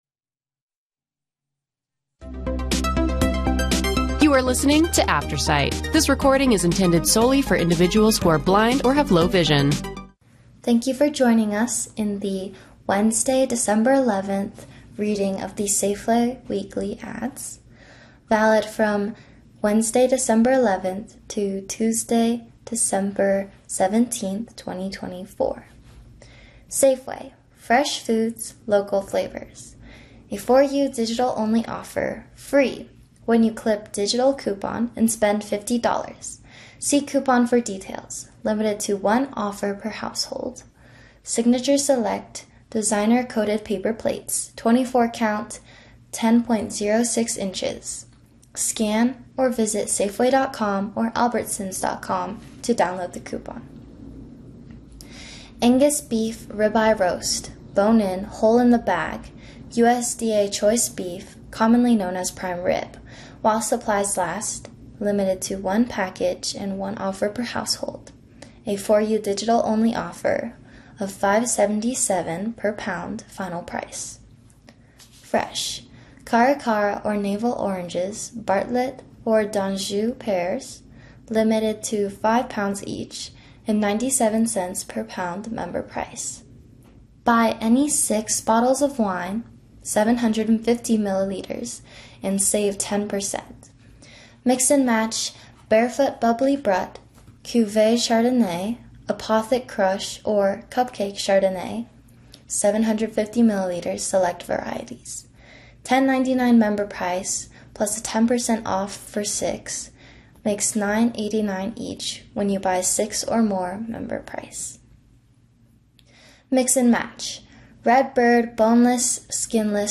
Play Rate Listened List Bookmark Get this podcast via API From The Podcast Safeway weekly ad in audio format.